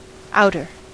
Ääntäminen
Ääntäminen US : IPA : [aʊ.tə(r)] Haettu sana löytyi näillä lähdekielillä: englanti Käännös Adjektiivit 1. ulkoinen 2. ulompi 3. ulommainen Muut/tuntemattomat 4. ulko- Määritelmät Adjektiivit Outside; external .